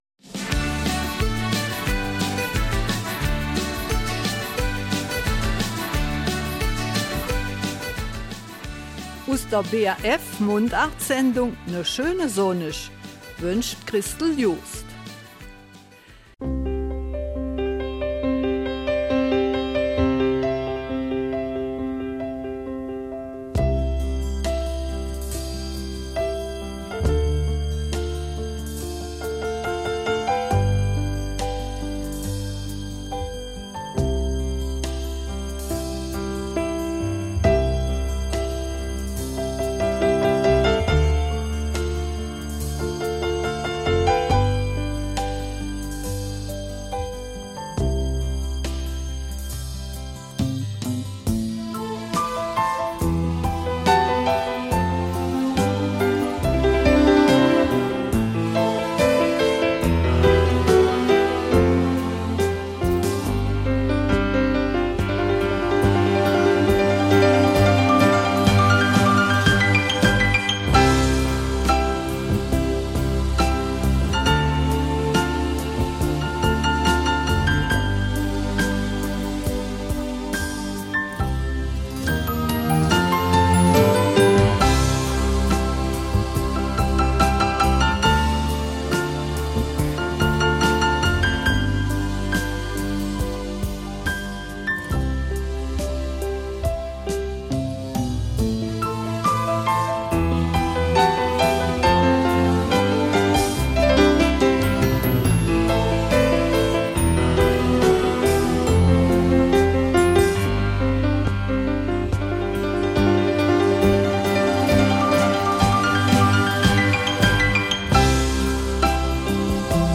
Eifeler Mundart: Erste-Hilfe-Kurs